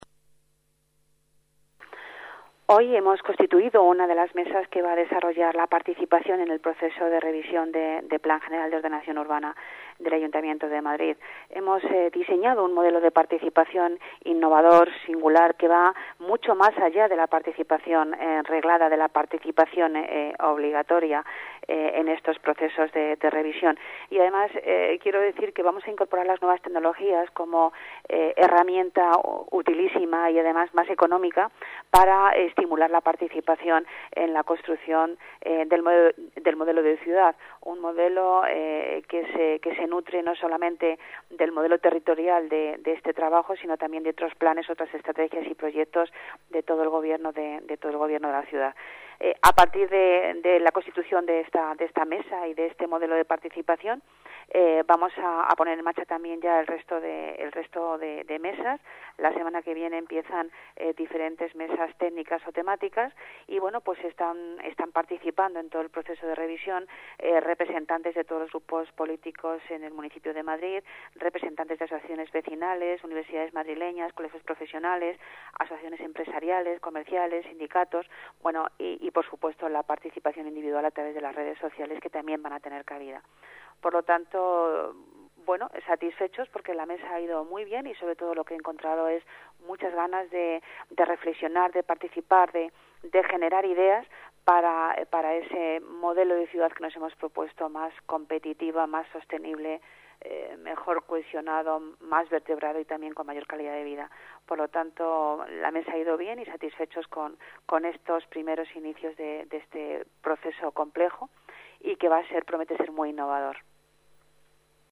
Nueva ventana:Declaraciones de Pilar Martínez